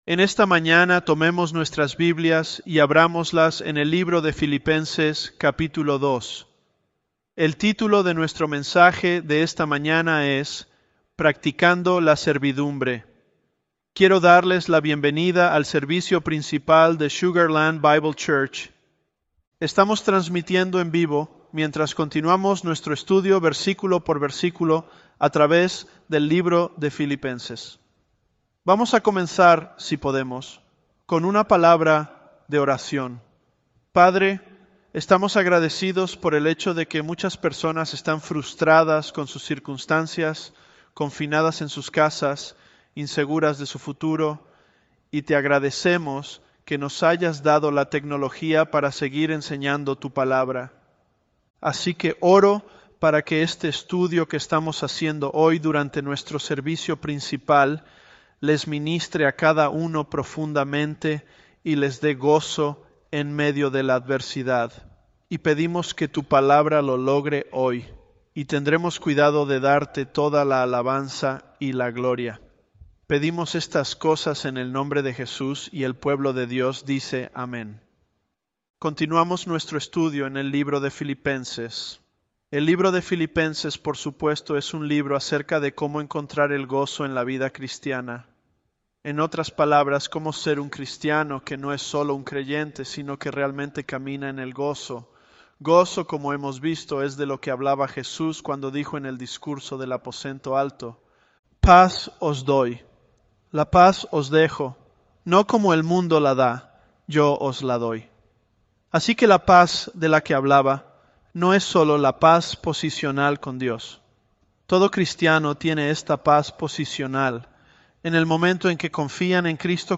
Sermons
Elevenlabs_Philippians005.mp3